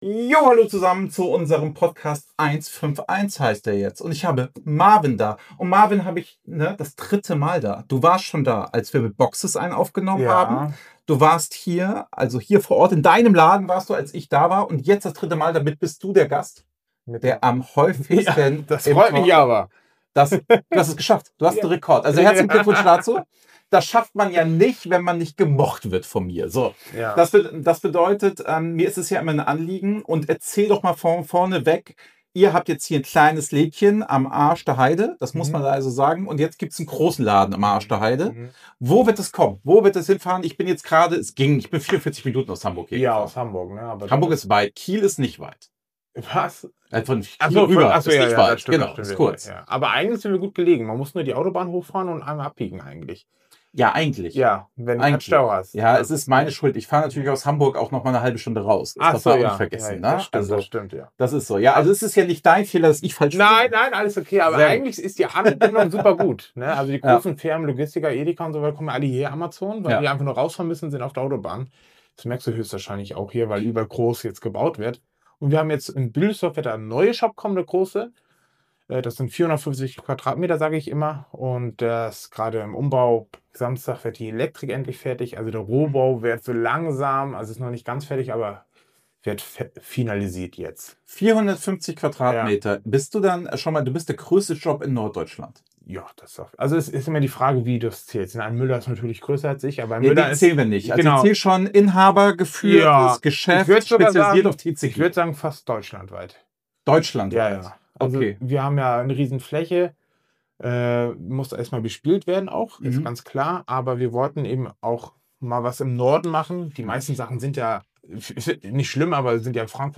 Ein ehrliches, langes Gespräch über Händler-Realität, Marktzyklen, japanische & chinesische Produkte, Whatnot, Streaming, Events und die Leidenschaft fürs Hobby.